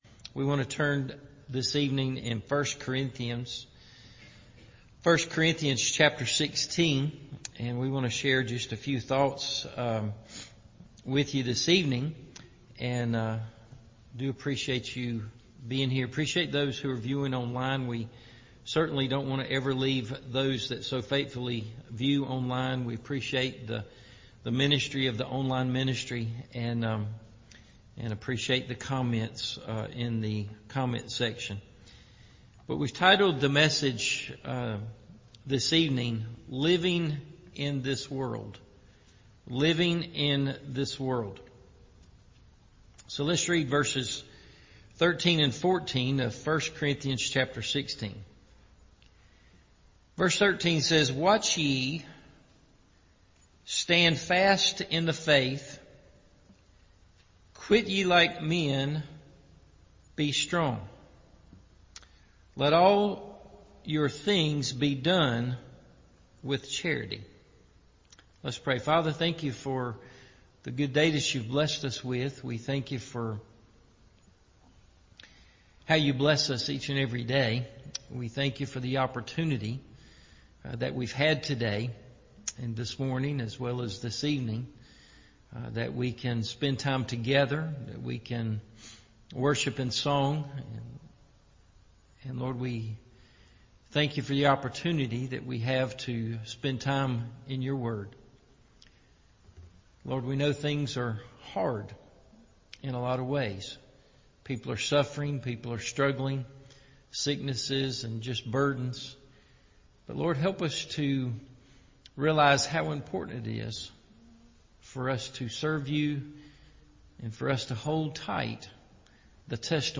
Living In This World – Evening Service